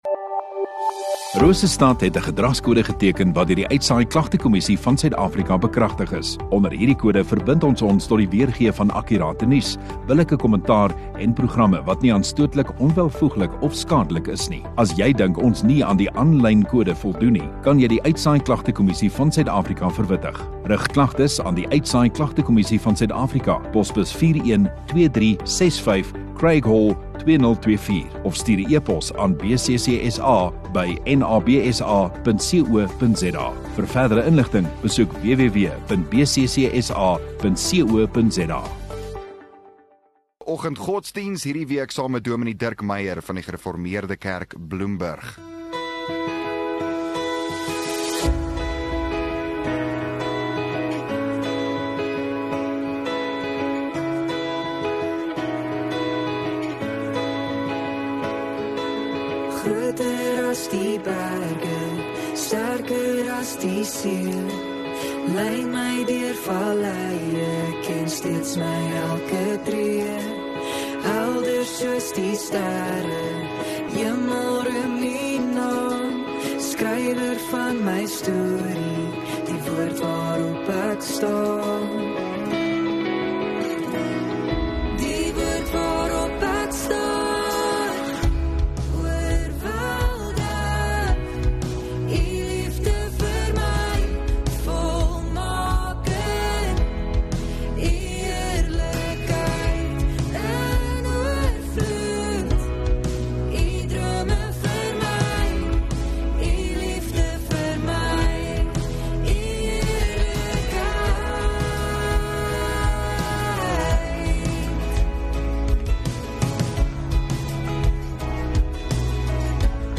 8 Aug Vrydag Oggenddiens